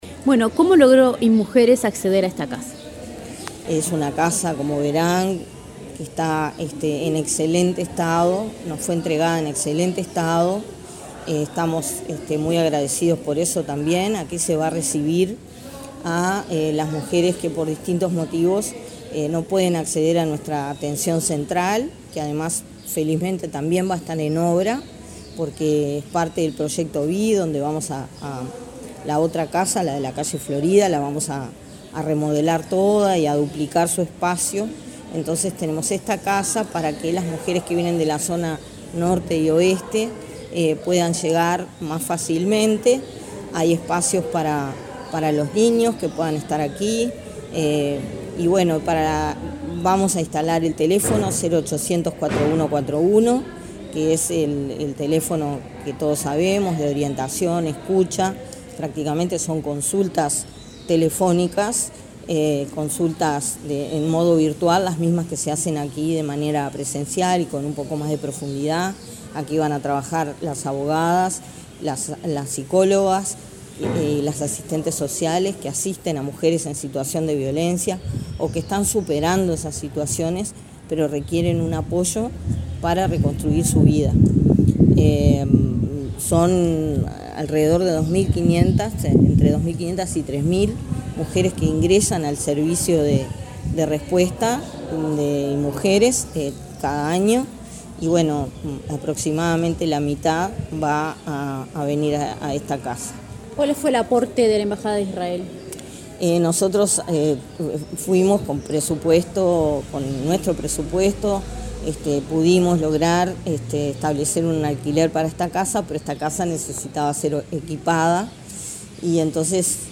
Entrevista a la directora de Inmujeres, Mónica Bottero
Entrevista a la directora de Inmujeres, Mónica Bottero 15/12/2022 Compartir Facebook X Copiar enlace WhatsApp LinkedIn La directora del Instituto Nacional de las Mujeres (Inmujeres), Mónica Bottero, dialogó con Comunicación Presidencial, luego de participar en la inauguración de la sede descentralizada de atención a mujeres en situación de violencia de Montevideo.